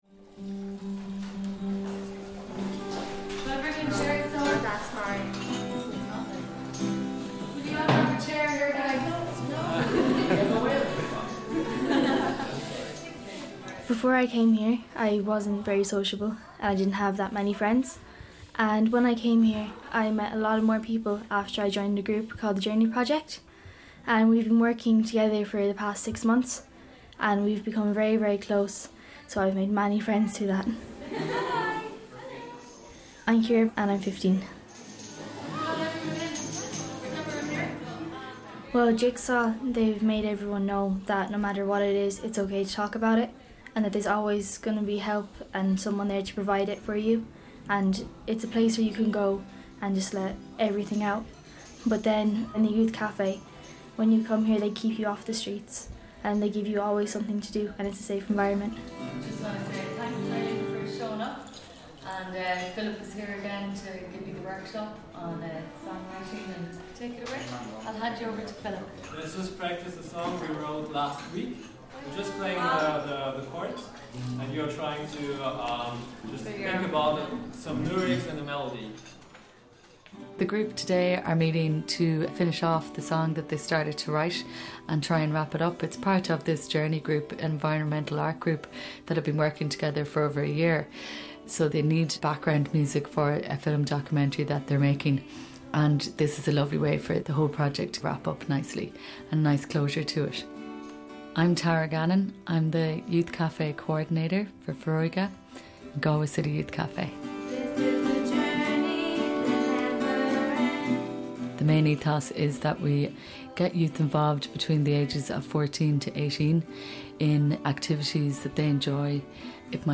This short form radio series is an Athena Media funded through the Broadcasting Authority of Ireland Sound and Vision Scheme.
This episode was recorded in Galway and is the final episode of Grassroots. The focus is on young people and the challenge of youth mental health.